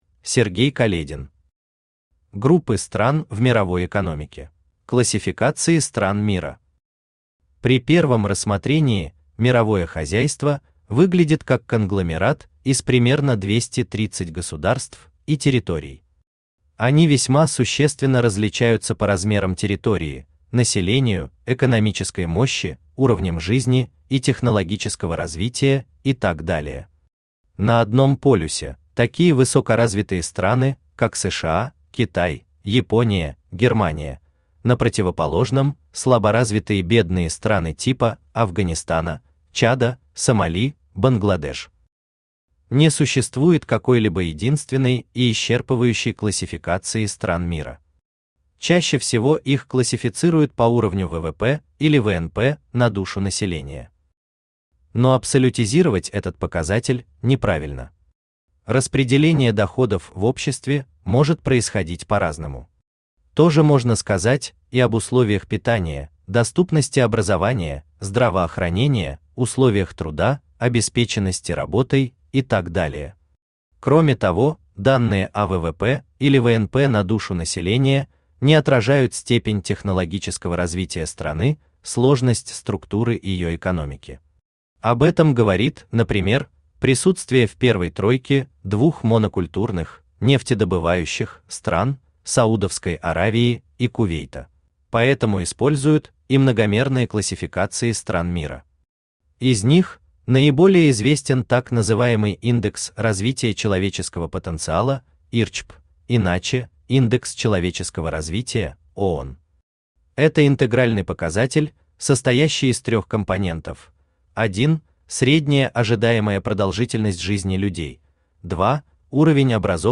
Aудиокнига Группы стран в мировой экономике Автор Сергей Каледин Читает аудиокнигу Авточтец ЛитРес.